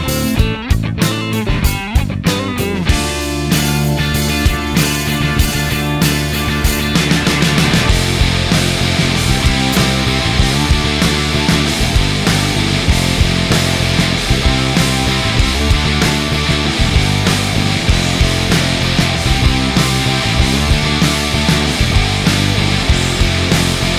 End Solo Cut Down And No Backing Vocals Rock 4:15 Buy £1.50